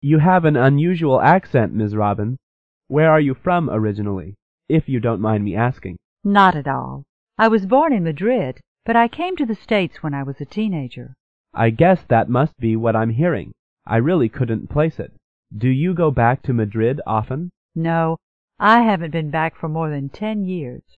办公室英语会话第29期-Asking about an accent 哪的口音